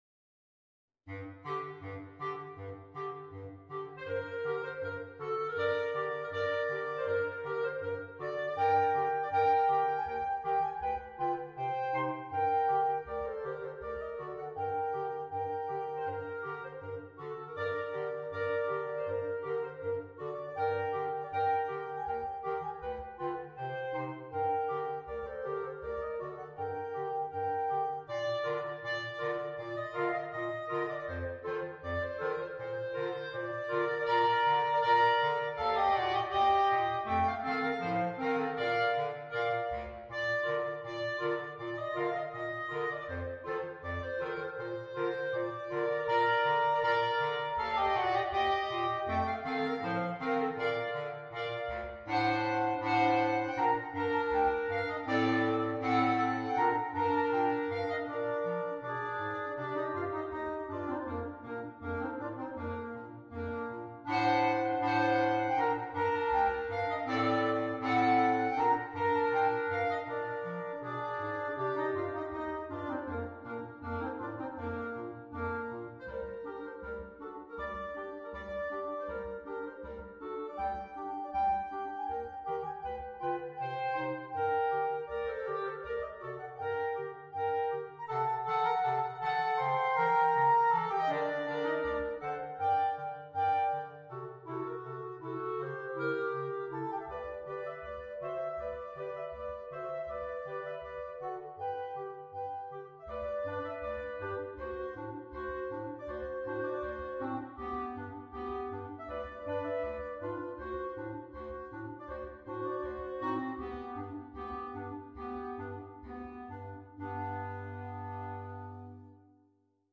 per quattro clarinetti